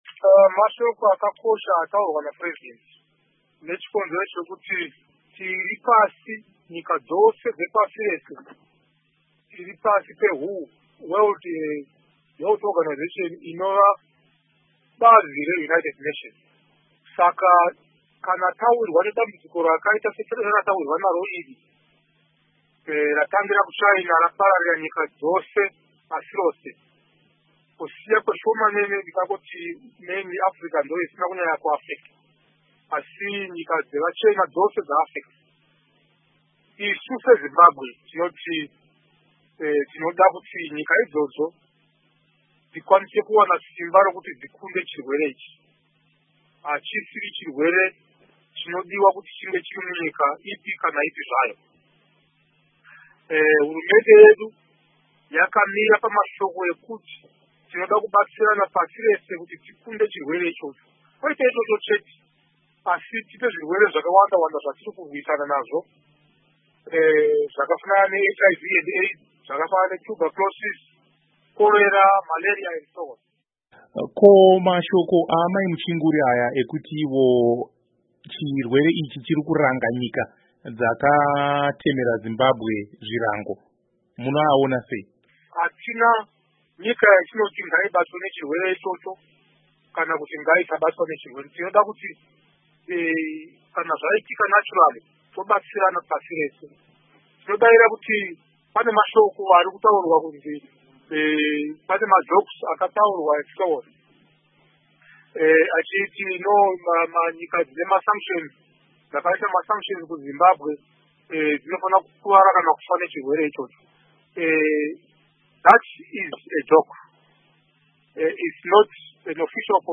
Hurukuro naVaEnergy Mutodi